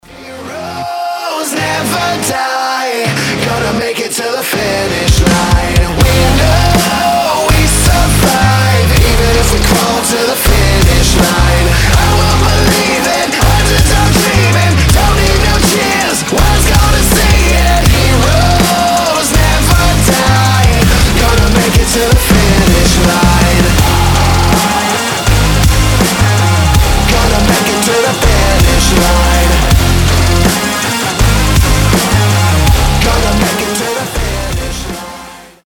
громкие
мощные
Alternative Metal
христианский рок